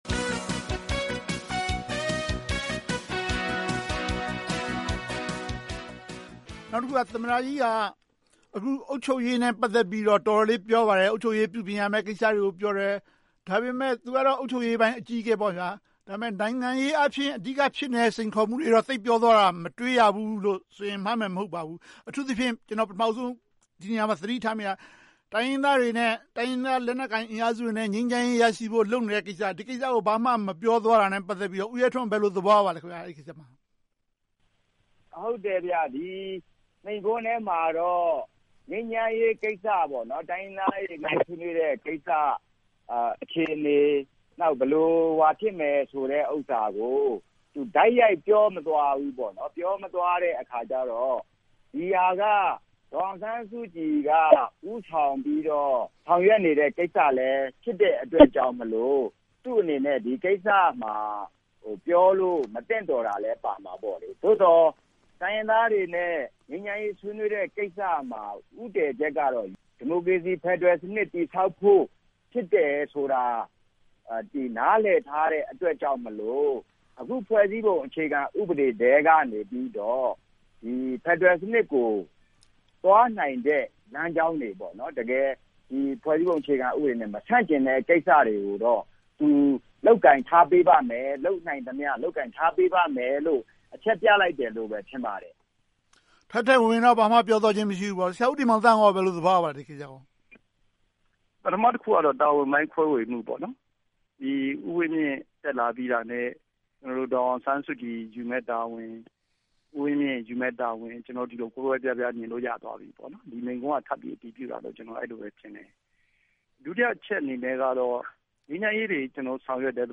ဆွေးနွေးသုံးသပ်ထားပါတယ်။